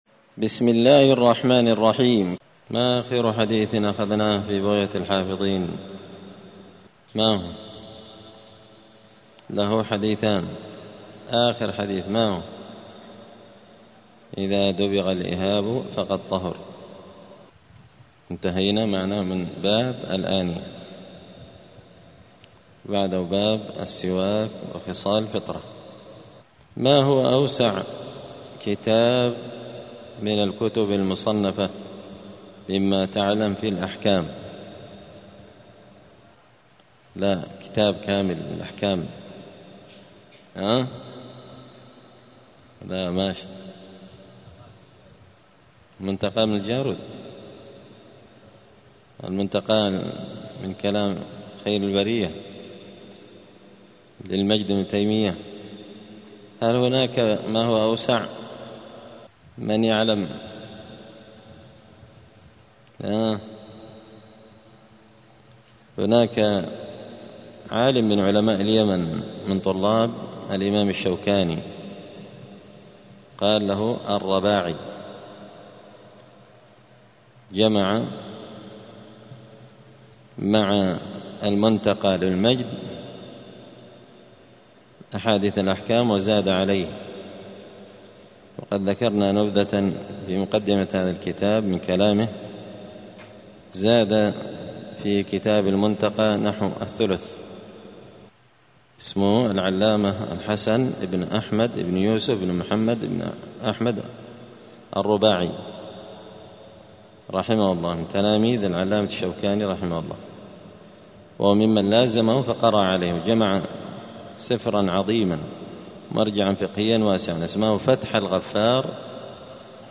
دار الحديث السلفية بمسجد الفرقان بقشن المهرة اليمن
*الدرس الخامس عشر (15) {باب السواك حكم السواك عند الصلاة…}*